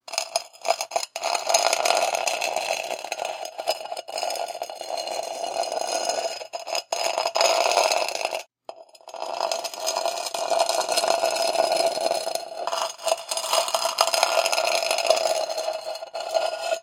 刮痧石
描述：将金属斧拖过石头/混凝土铺路砖。听起来像是来自埃及/印第安纳琼斯风格的诱饵陷阱。 用Rode VideoMic Pro录制成Zoom H5录音机。
标签： 粗糙 混凝土
声道立体声